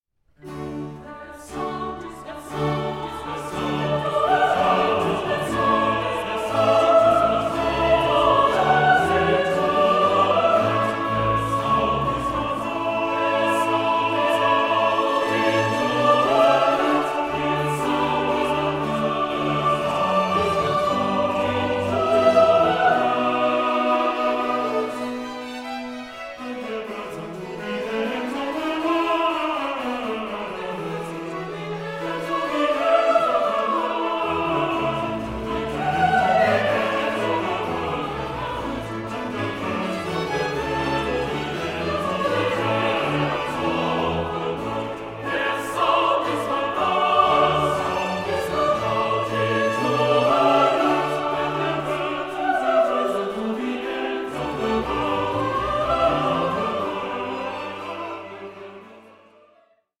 Air